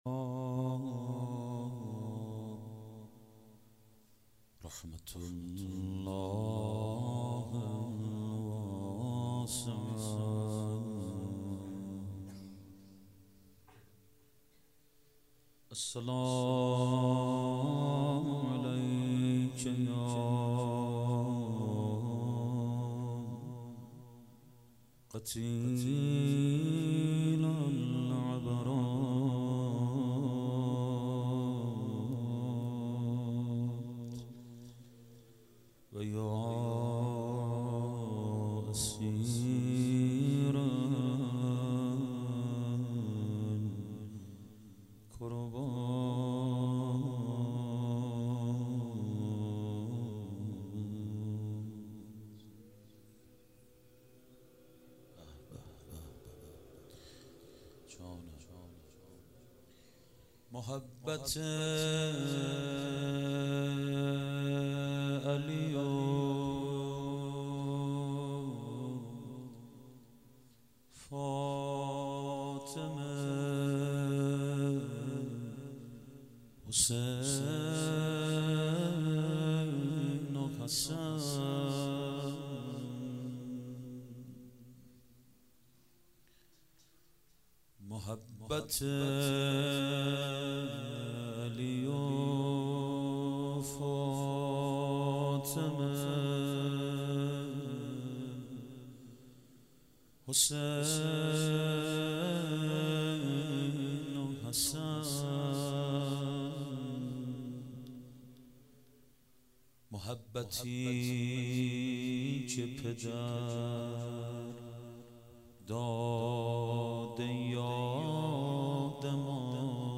چهاراه شهید شیرودی حسینیه حضرت زینب (سلام الله علیها)
مراسم شام شهادت امام سجاد(ع)95